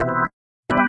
键盘 " 风琴09
44khz 16位立体声，无波块。
Tag: 键盘 器官 DB33